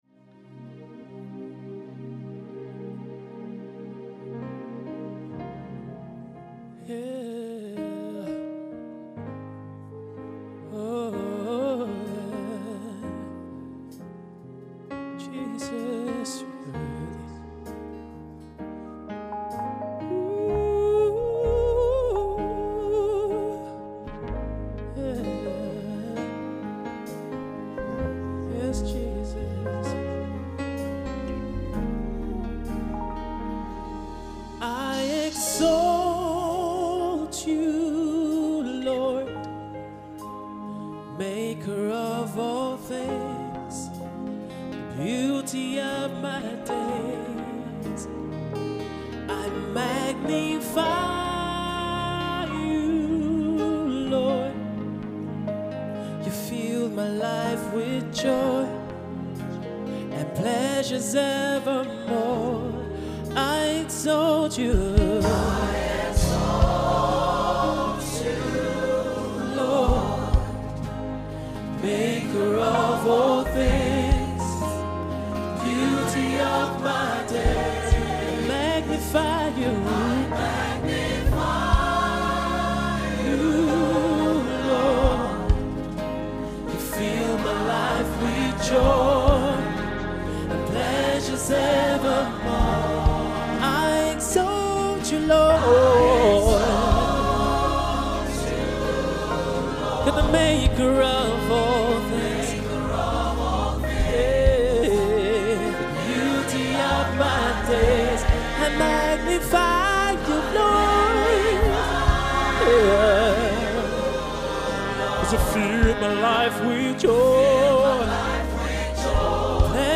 Lyrics, Praise and Worship